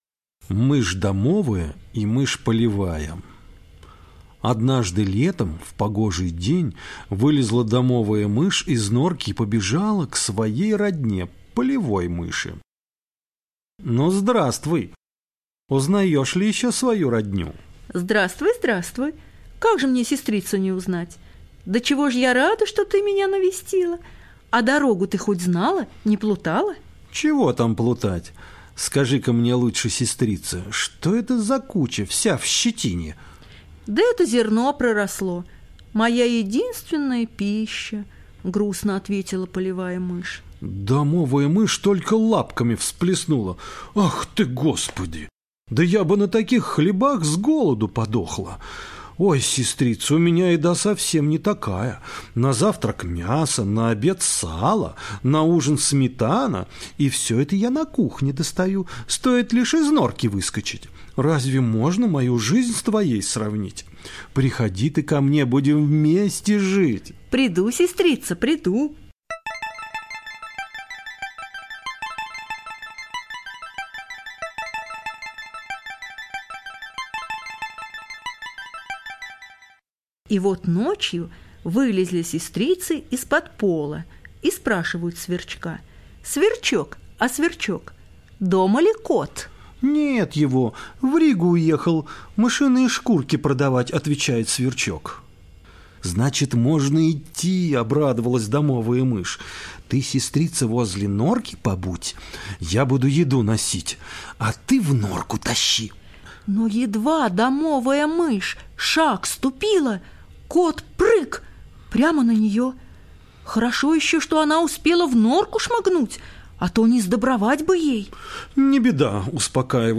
Мышь домовая мышь полевая – латышская аудиосказка